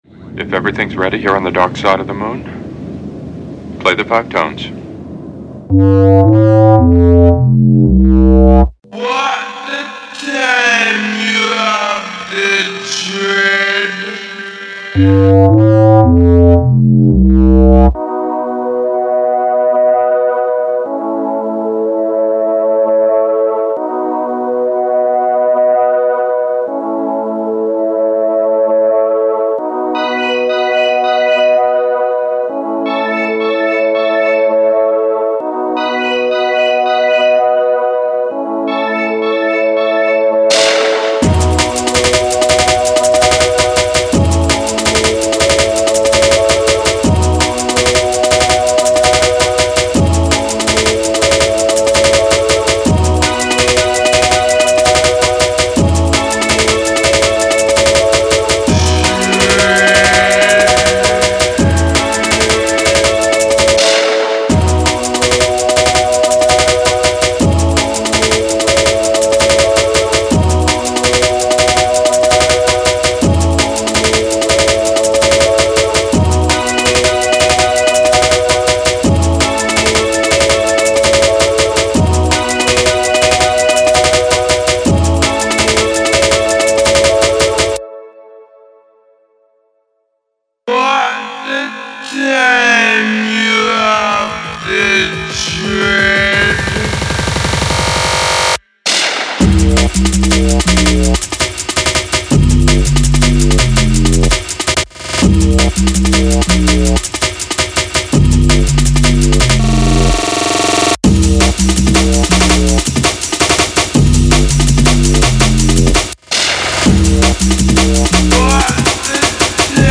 Jungle anthems